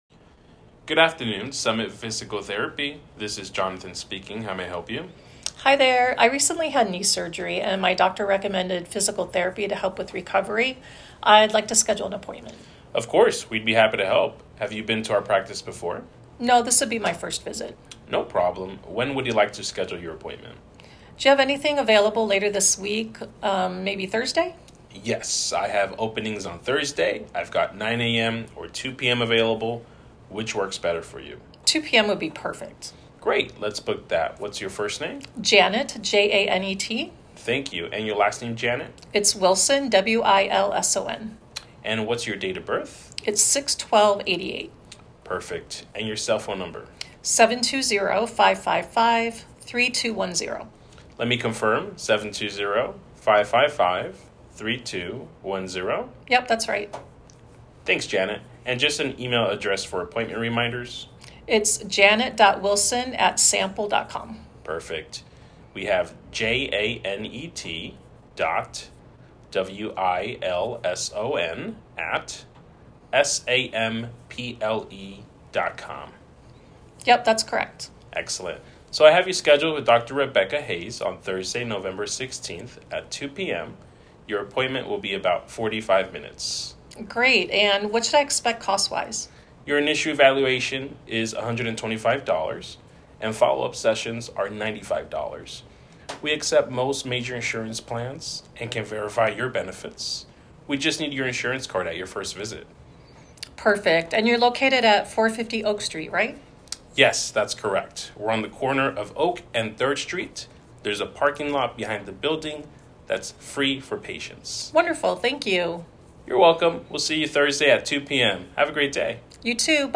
virtual-receptionist-appointment-booking-call-sample-MyDiary.mp3